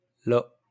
IPA/lo/; polska: lo